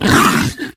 flesh_pain_0.ogg